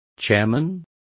Complete with pronunciation of the translation of chairman.